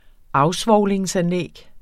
Udtale [ ˈɑwˌsvɒwˀleŋs- ]